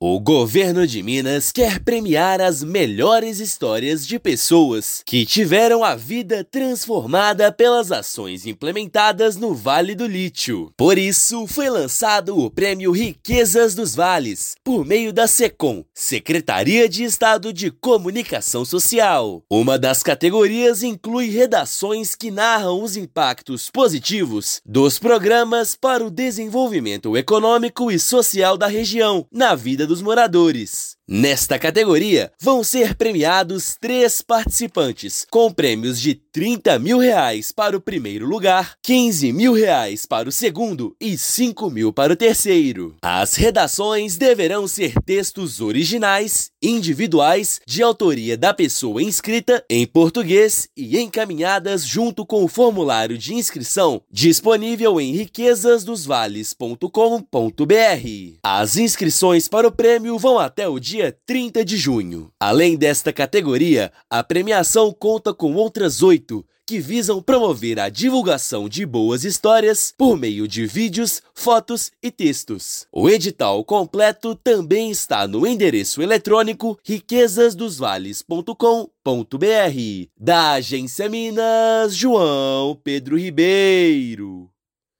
Categoria deverá ter textos originais com histórias que mostrem os impactos positivos na região. Ouça matéria de rádio.